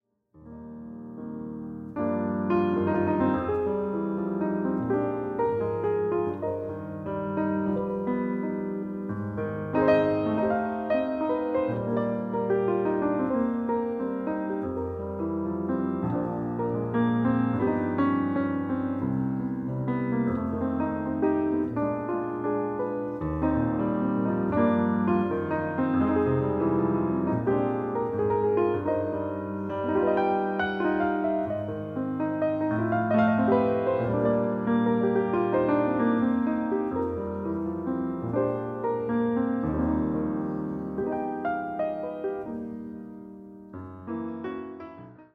いわゆる「イージーリスニング」と呼ばれる、1920〜50年代にヒットした曲を中心とした選曲。
ハワイに行ったことがある人もない人も、優しい海風のようなこのピアノに、ただ身を任せてください。